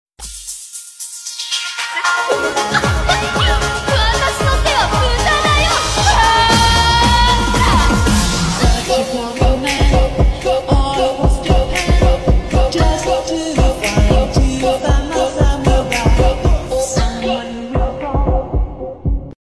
top 13 KO sound effects free download